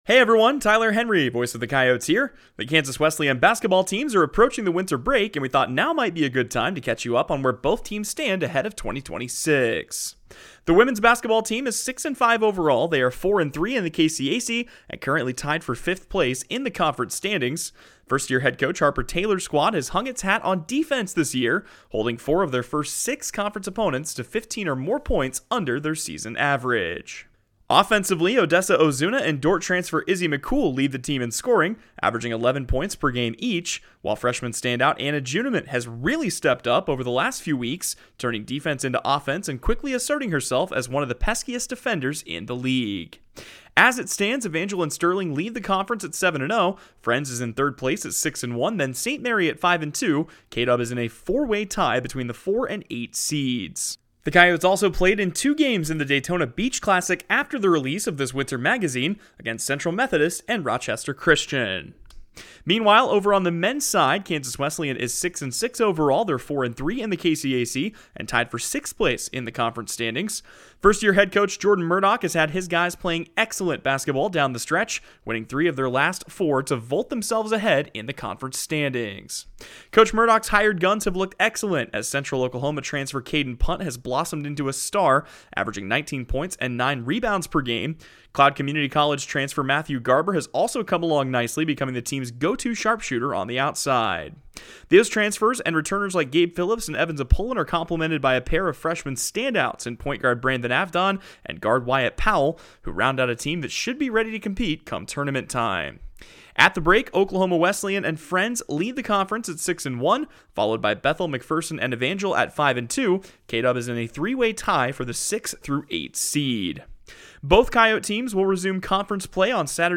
Audio recap